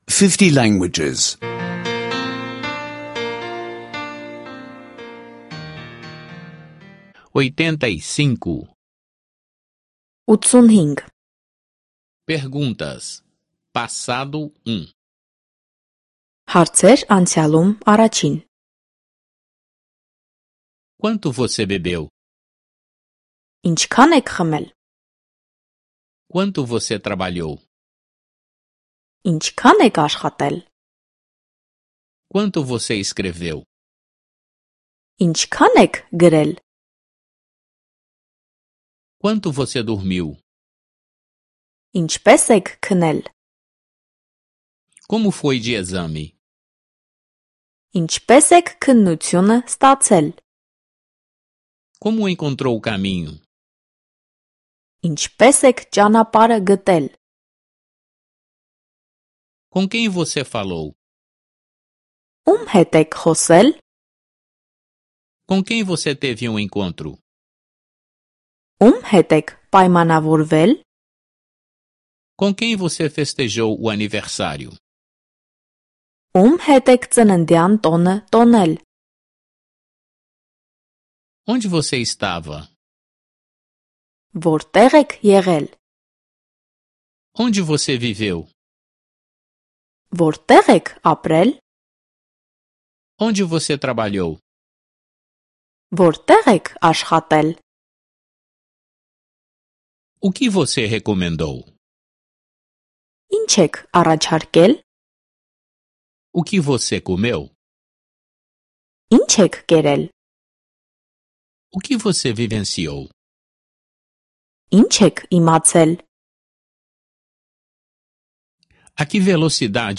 Aulas de armênio em áudio — download grátis